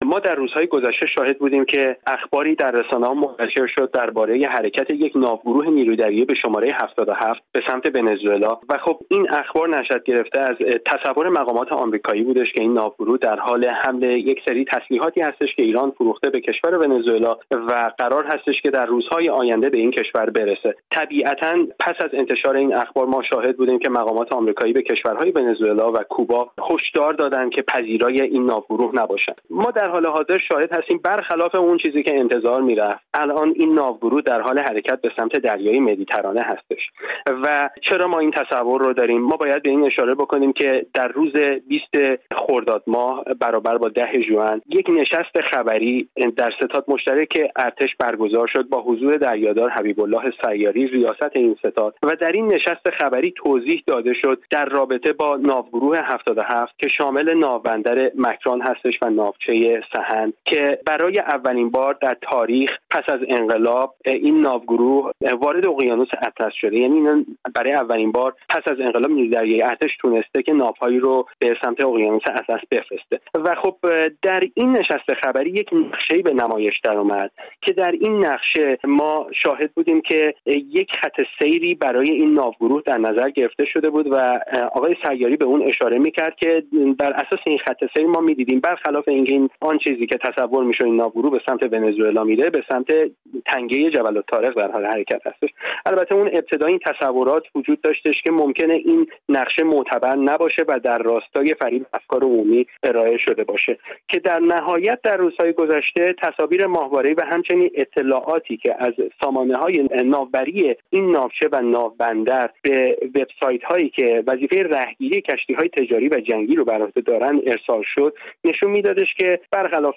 کارشناس نظامی و دفاعی